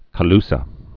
(kə-lsə)